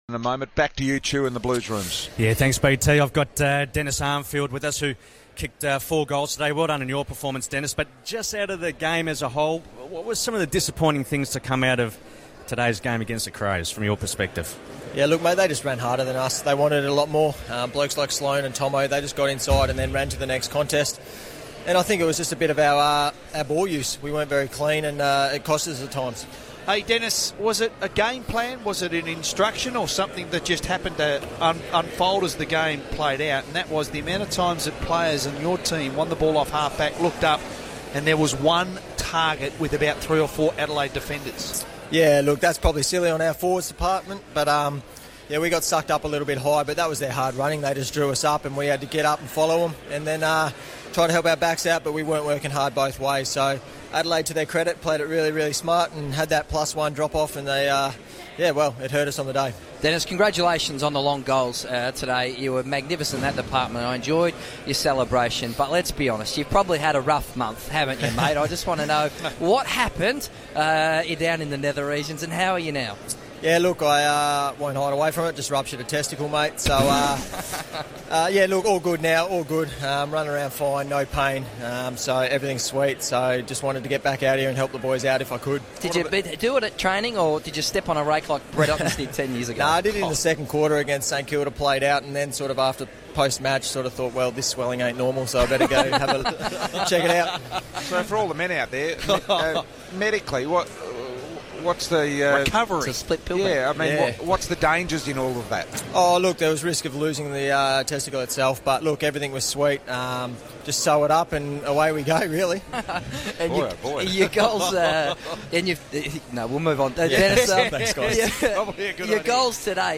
Carlton forward Dennis Armfield speaks to Triple M in the rooms after the Blues' 60-point loss to Adelaide.